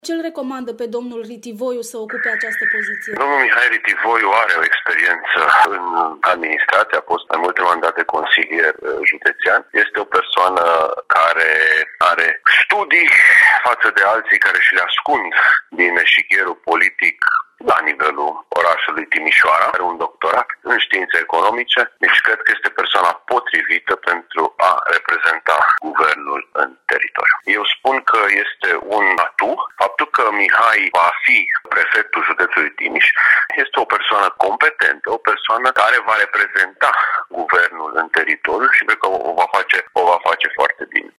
Mihai Ritivoiu, președinte al PSD Timișoara, a fost singurul nume propus în filială pentru ocuparea acestei poziții, spune senatorul Sebastian Răducanu: